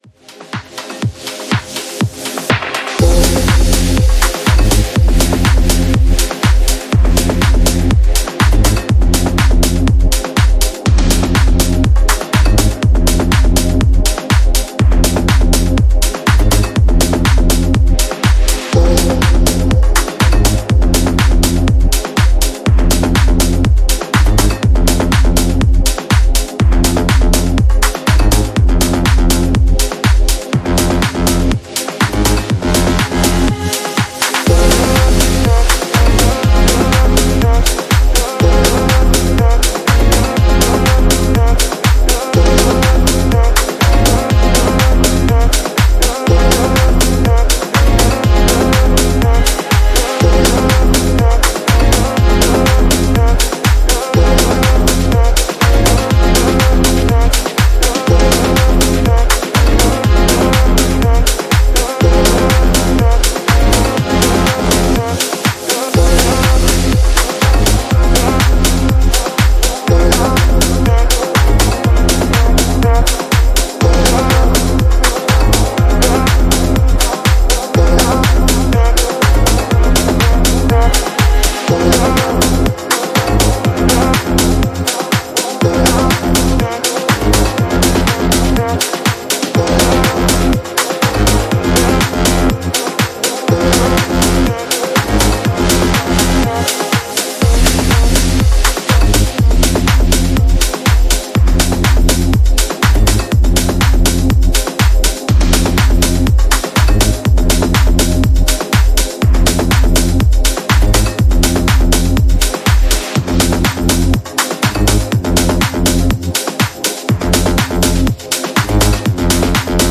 Genre: Melodic House